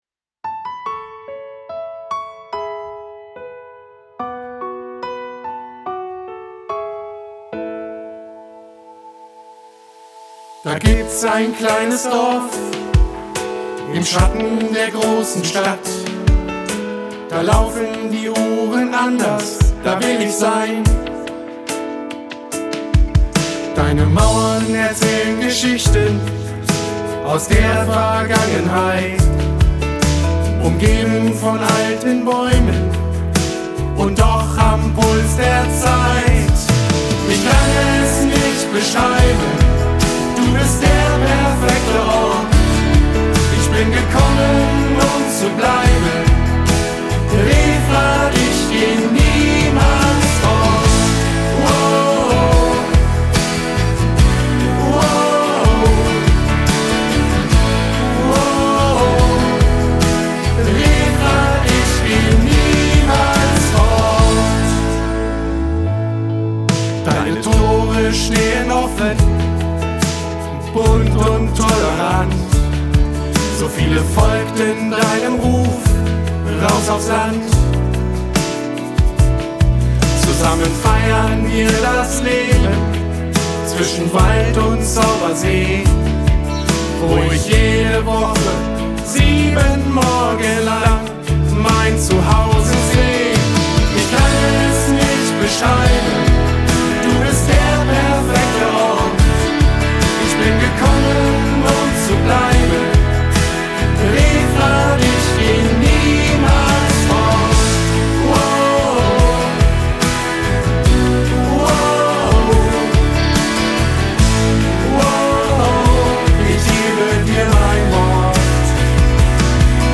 eine Hymne für alle die Räfed im Herzen tragen.